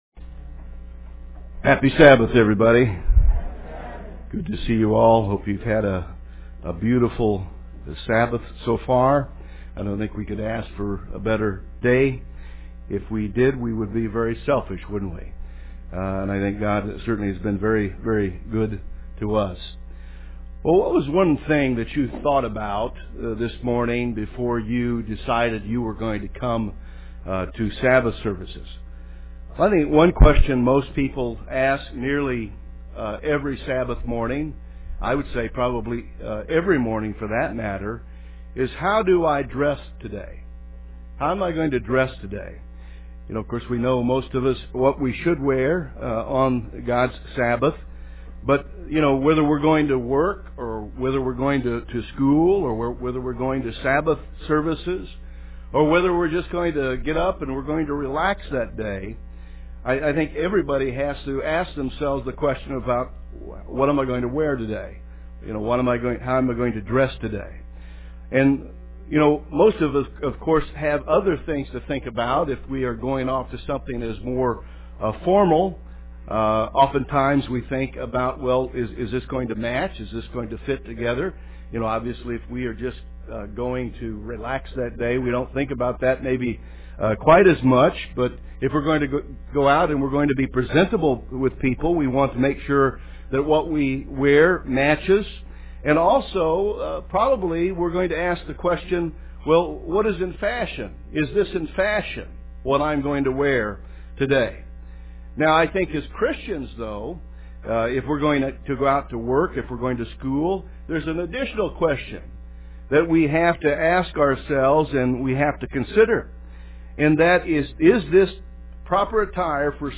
Given in San Francisco Bay Area, CA
UCG Sermon Studying the bible?